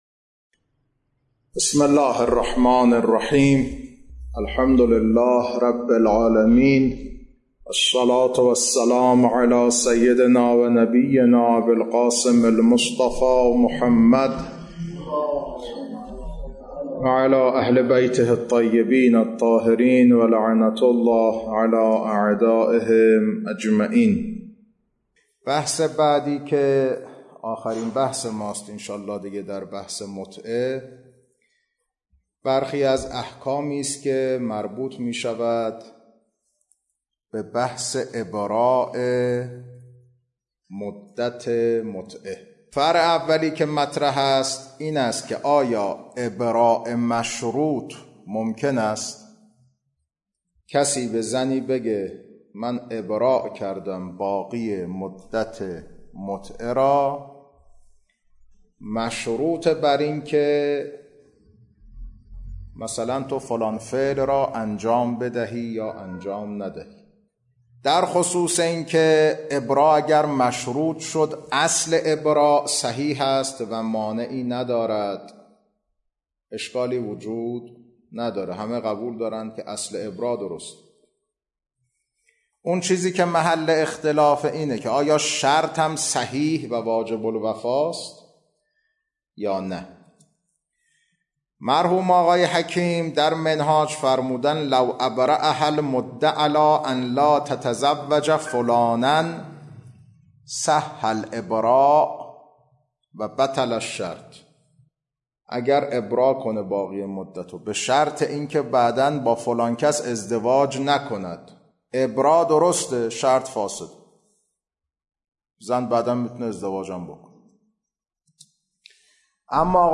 کلاس‌ها خارج فقه